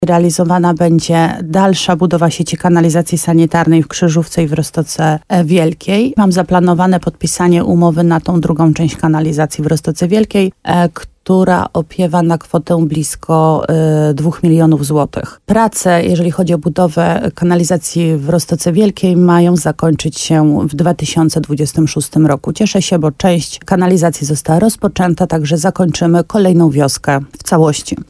Jak powiedziała w programie Słowo za Słowo w radiu RDN Nowy Sącz wójt Marta Słaby, budżet zakłada kontynuację części inwestycji i stabilne funkcjonowanie usług publicznych.